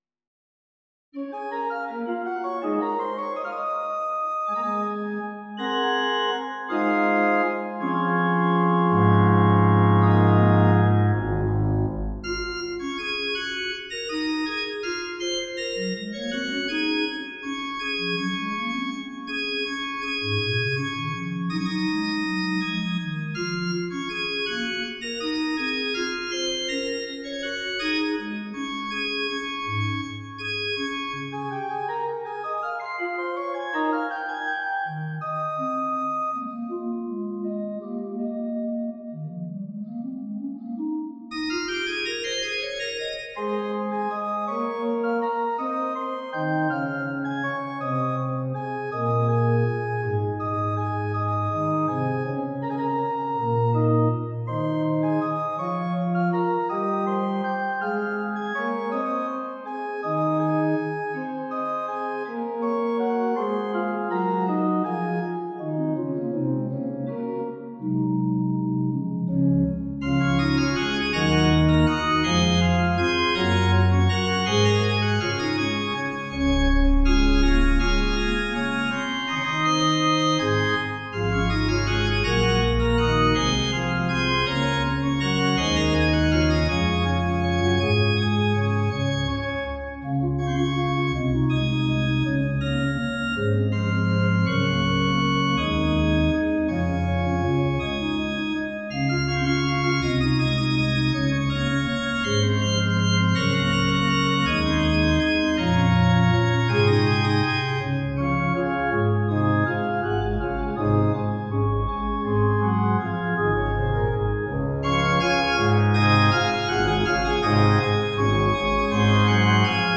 for organ
The head motive of this jig tune is of simple, rising triadic structures finished by simple fifths. In addition to the expected dominant for an answer, the mediant minor is also used.